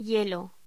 Locución: Hielo
voz